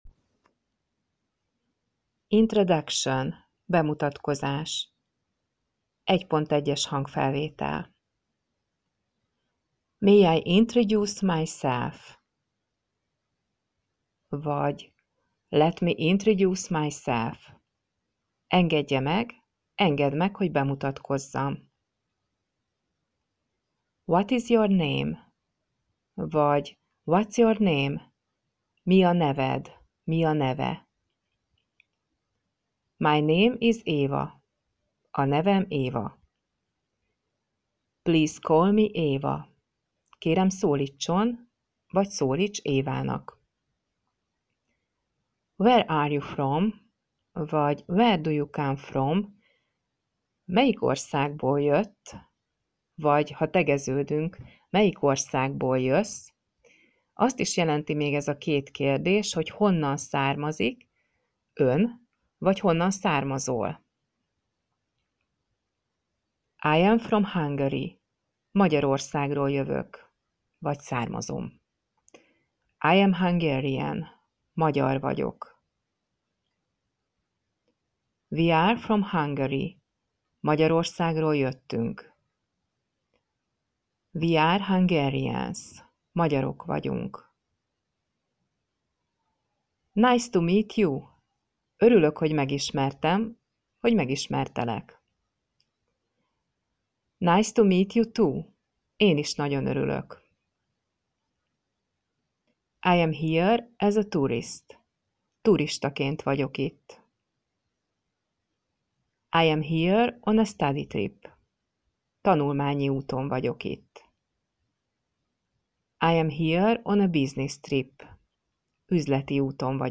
lassan, tagoltan, jól artikuláltan, kellemes hangon mondja el a szavakat, kifejezéseket, mondatokat